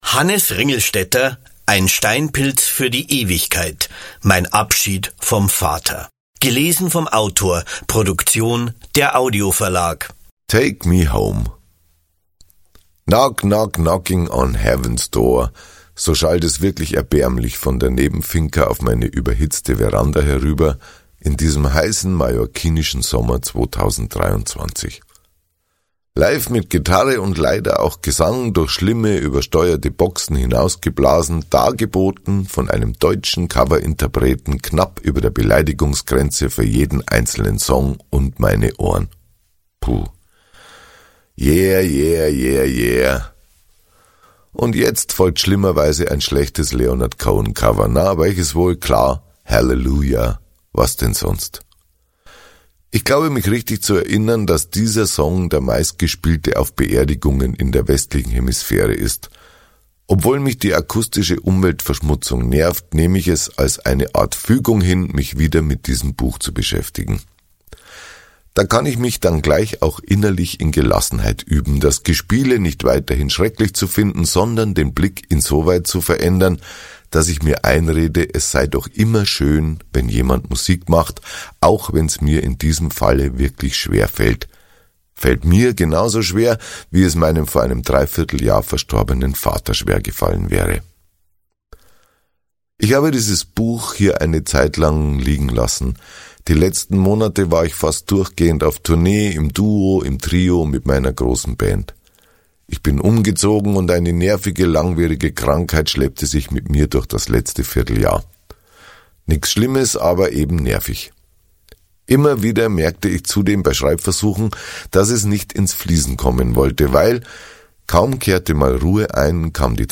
Hannes Ringlstetter (Sprecher)
Ungekürzte Autorenlesung mit Hannes Ringlstetter